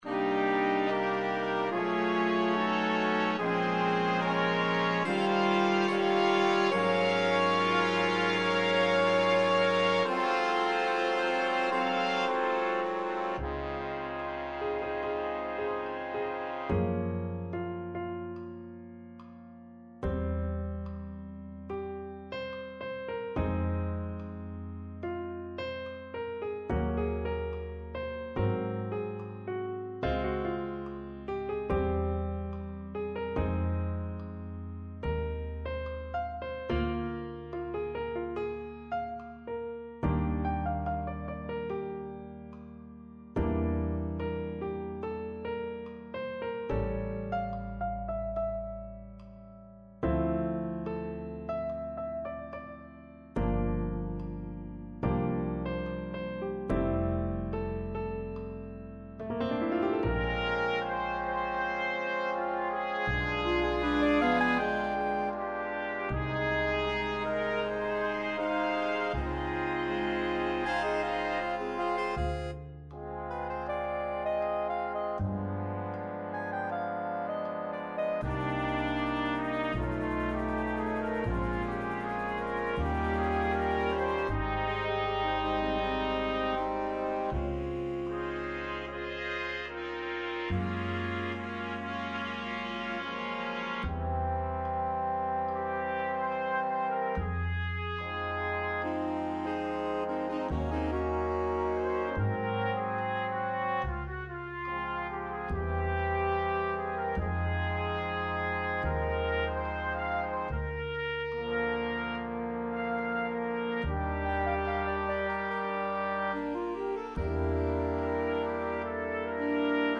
Skladby pro Big Band / Big Band Scores
computer demo